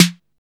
TS Snare_3.wav